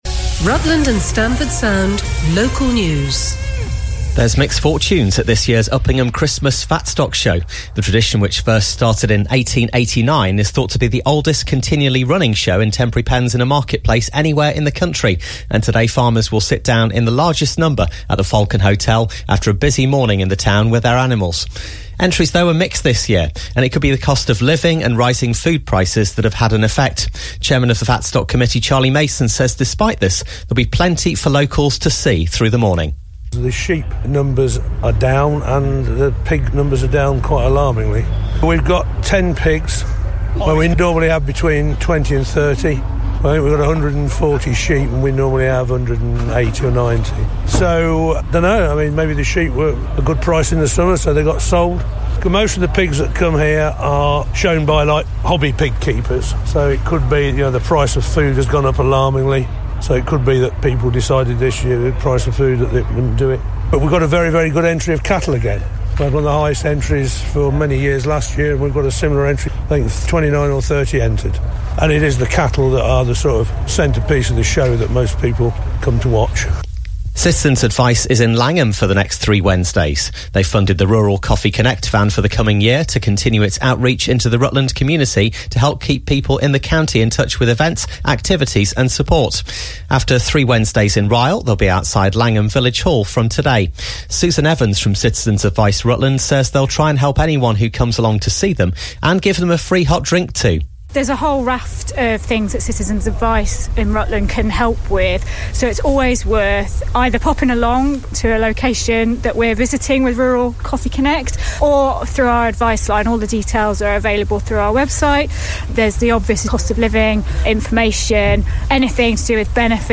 For more on this news item and to hear the rest of the morning’s news bulletin, please click on the Play Now button.